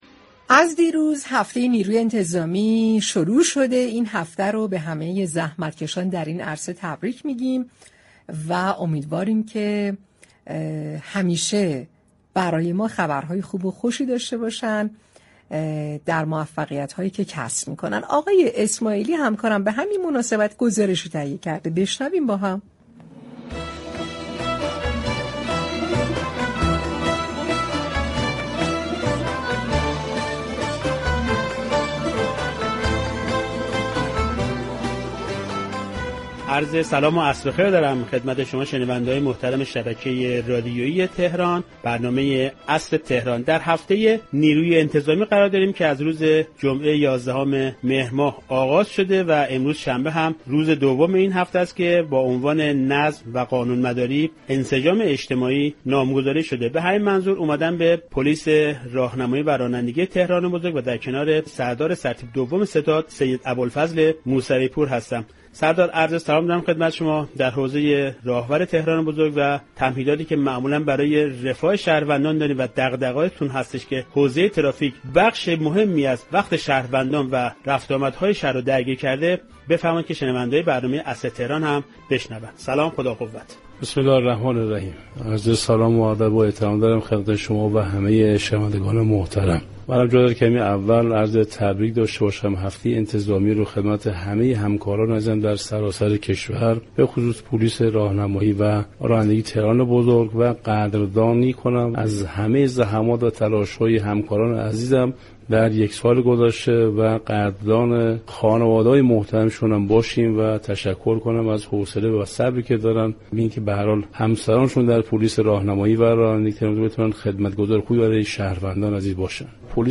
در پایان این گفت‌وگو، رئیس پلیس راهور تهران بزرگ از رسانه‌ها به‌ویژه رادیو تهران برای همكاری و اطلاع‌رسانی در حوزه ترافیك تشكر كرد و یاد شهدای فرماندهی انتظامی تهران بزرگ، از جمله سردار علیرضا شهید لطفی جانشین سازمان اطلاعات فراجا را كه در طی جنگ تحمیلی 12 روزه به فیض شهادت نائل شد را گرامی داشت.